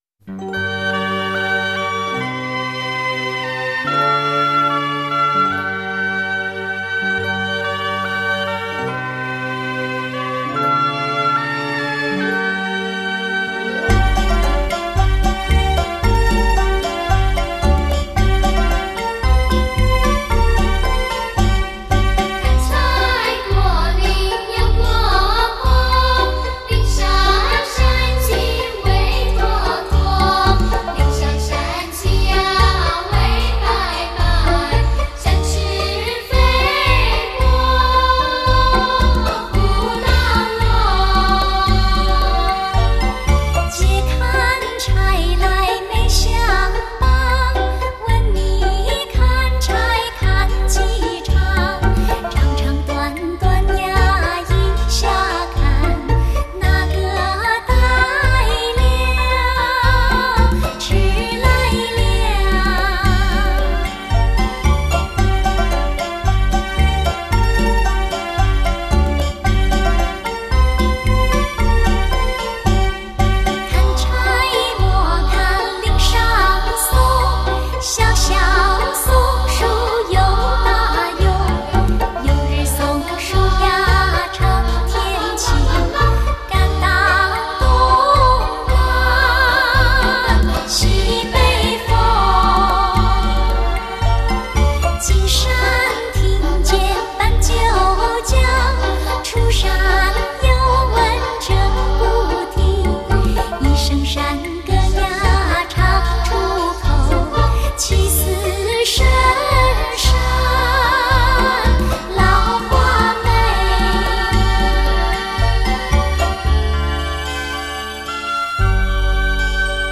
混声合唱
用现代音乐编配混声唱法精心制作